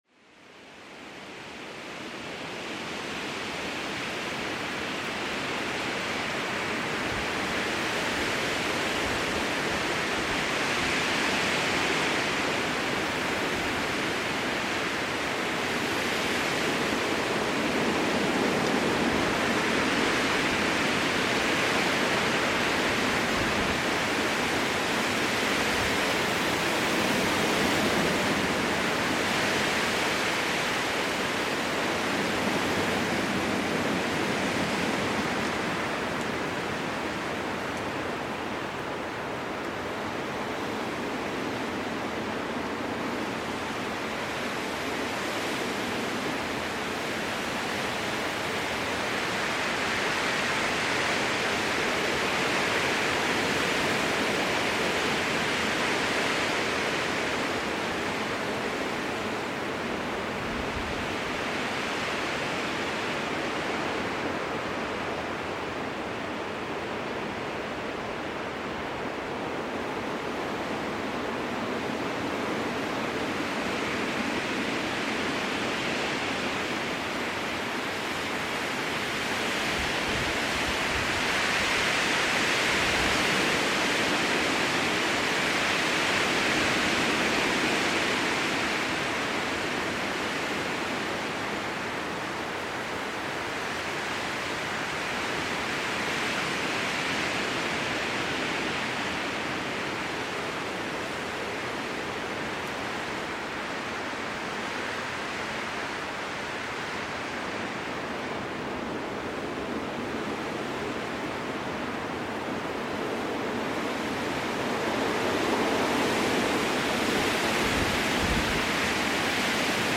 SEKUNDEN-BERUHIGUNG: Fichten-Power mit wildem Rauschen